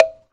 描述：单木蛙块命中
Tag: 砌块 木材 单身 打击乐 青蛙 命中